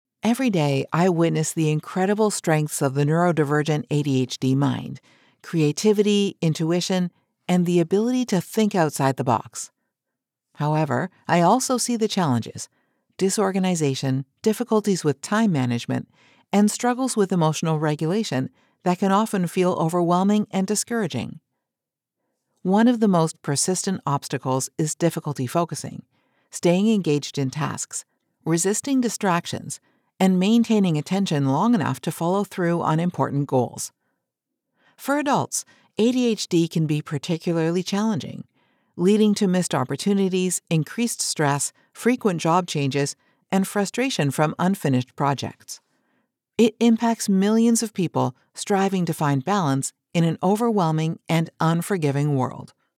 Audiobook- Non-Fiction, conversational
My voice has been described as a “warm hug".